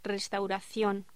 Título Locución: Restauración
Sonidos: Voz humana